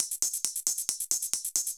Index of /musicradar/ultimate-hihat-samples/135bpm
UHH_ElectroHatA_135-03.wav